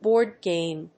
/ˈbɔˌrdgem(米国英語), ˈbɔ:ˌrdgeɪm(英国英語)/
アクセントbóard gàme